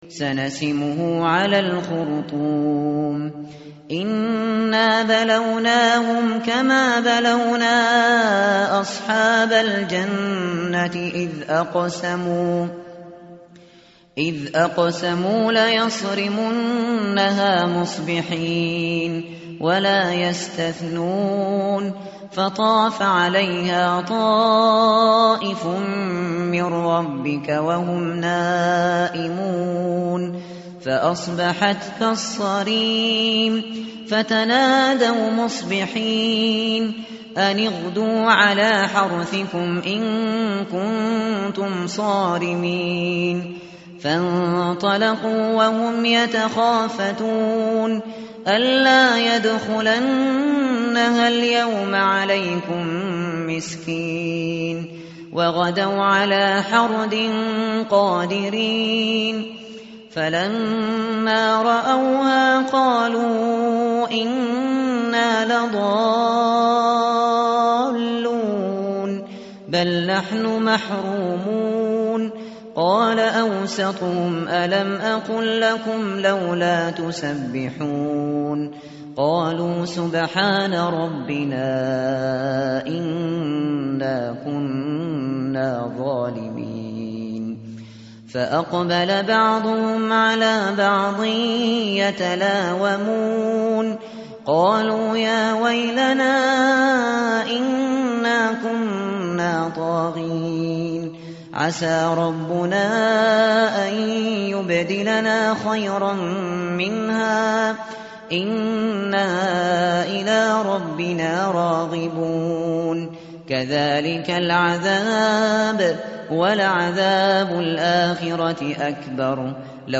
متن قرآن همراه باتلاوت قرآن و ترجمه
tartil_shateri_page_565.mp3